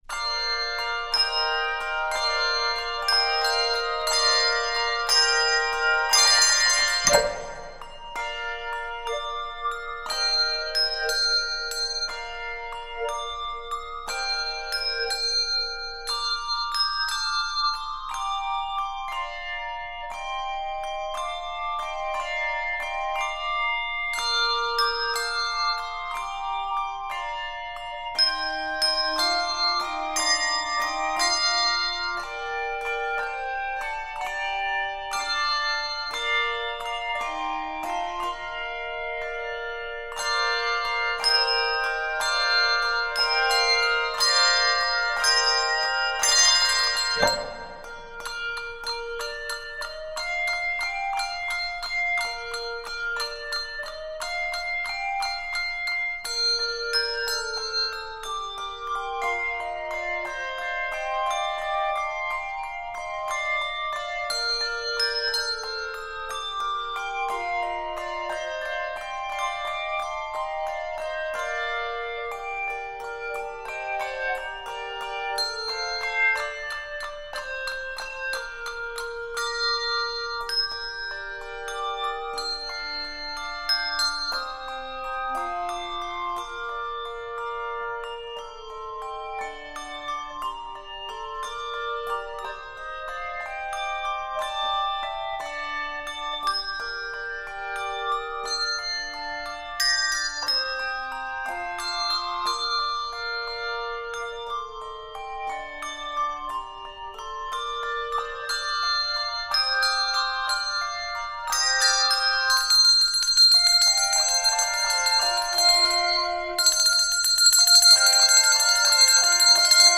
relaxed arrangement
Key of Bb Major.